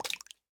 minecraft / sounds / mob / frog / tongue1.ogg
tongue1.ogg